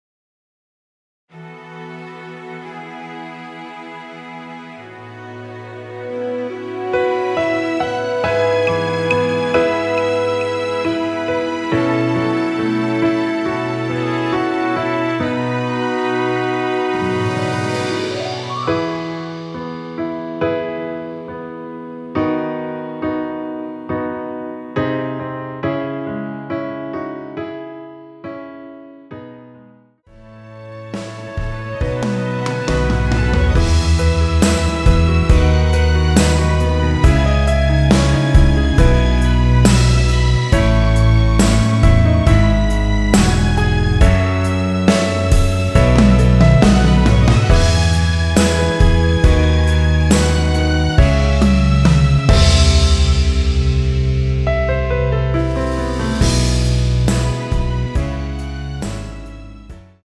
여성분이 부르실수 있는키의 MR입니다.
원키에서(+3)올린 MR입니다.
앞부분30초, 뒷부분30초씩 편집해서 올려 드리고 있습니다.